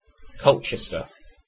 Ääntäminen
Ääntäminen UK UK : IPA : /ˈkəʊltʃɛstə/ Haettu sana löytyi näillä lähdekielillä: englanti Käännöksiä ei löytynyt valitulle kohdekielelle.